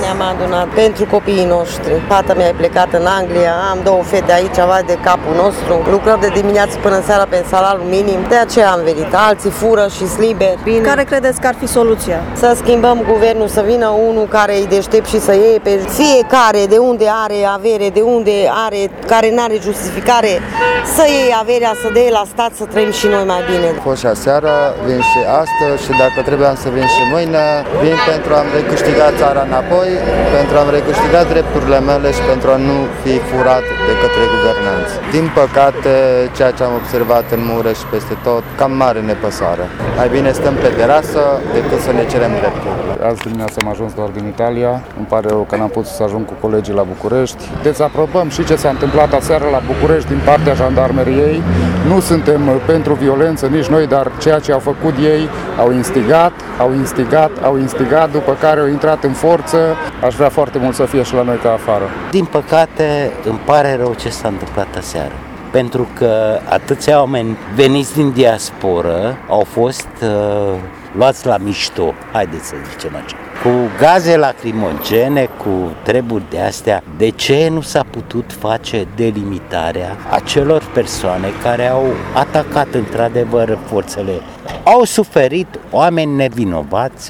Protestatarii s-au adunat încă de la ora 19, cu pancarte și scandări împotriva Guvernului și a politicienilor.
Cei mai mulți și-au exprimat solidaritatea cu protestatarii din București și dezacordul față de acțiunile în forță ale jandarmilor: